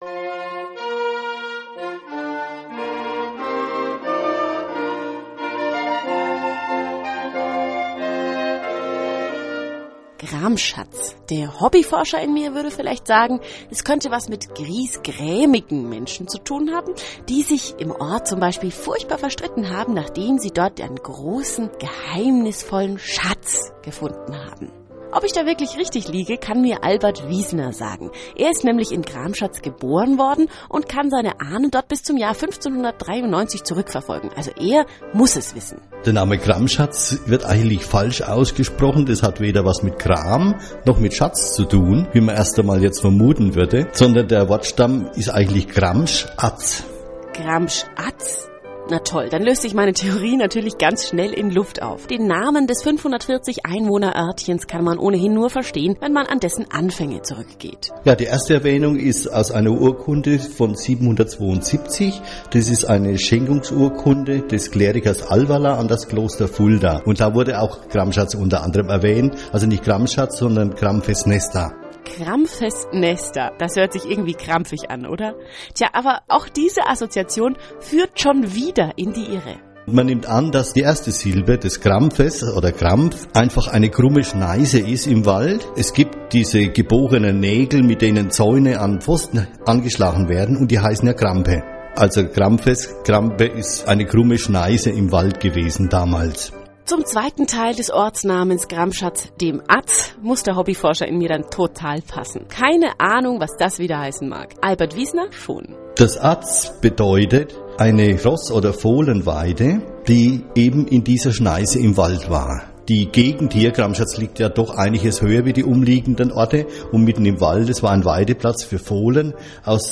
Radiobeitrag (mp3: 1,48 MB)